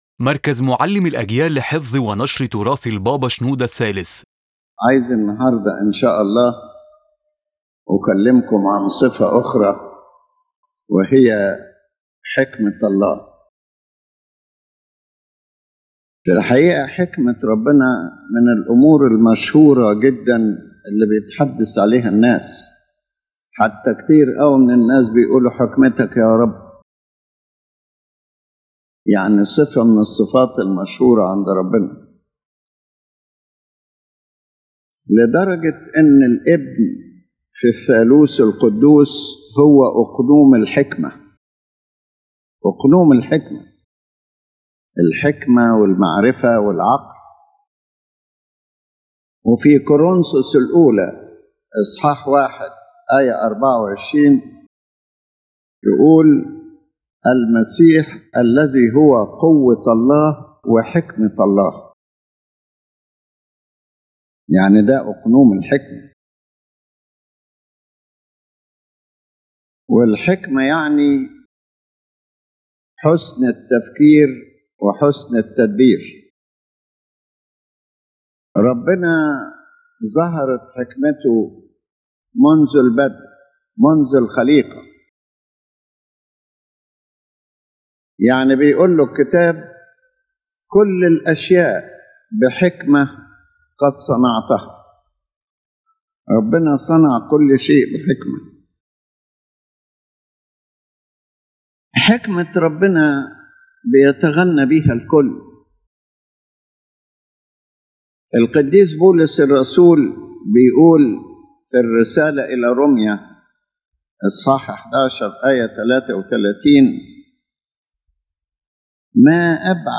His Holiness Pope Shenouda III speaks about one of the most glorious attributes of God—Wisdom—noting that people often praise it saying, “Your wisdom, O Lord.”